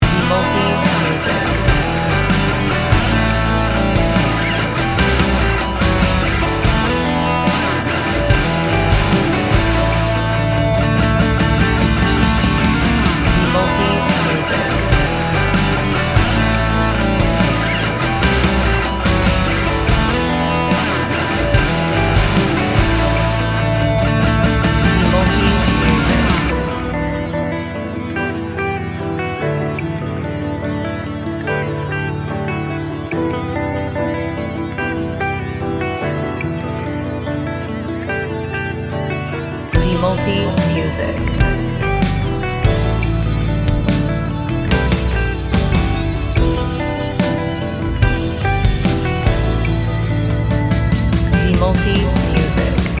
Gym Music Instrumental